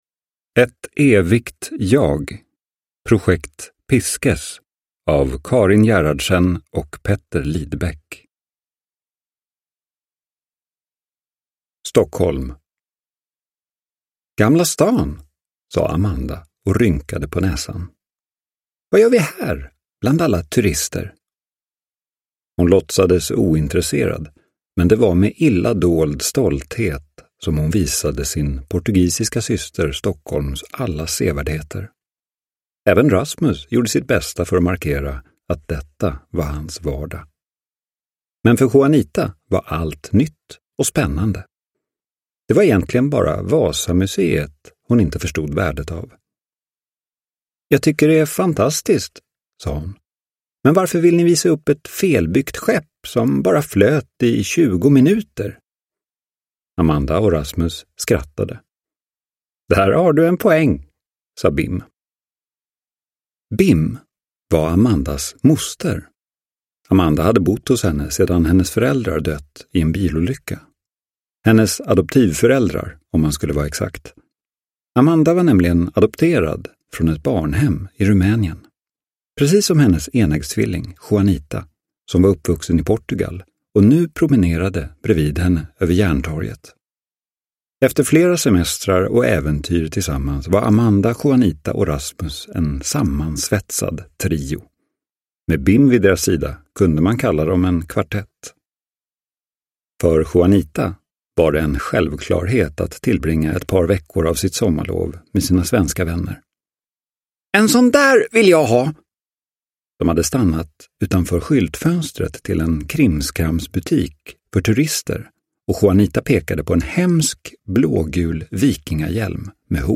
Ett evigt jag : Projekt Pisces – Ljudbok – Laddas ner